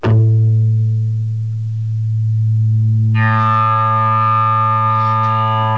I made these sounds with my Sound Blaster wave studio and my Sound Recorder on Windows.
A Buzz in I Feel Fine  Critical Stop
abuzz.wav